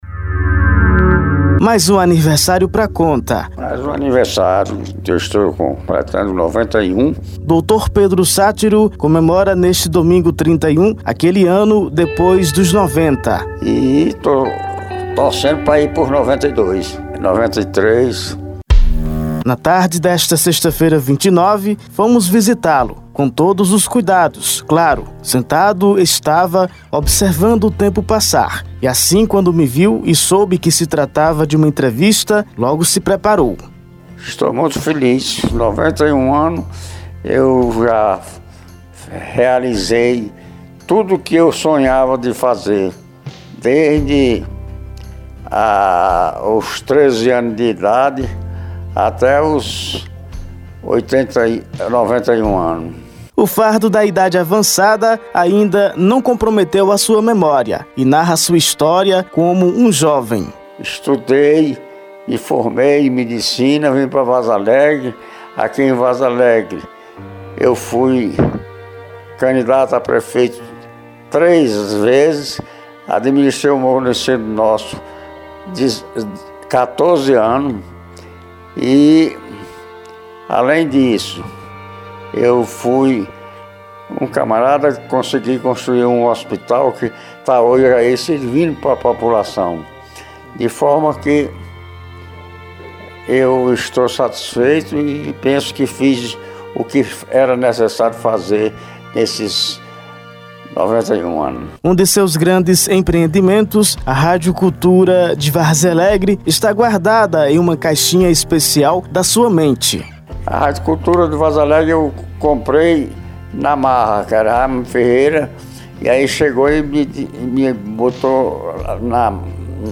realizou uma reportagem especial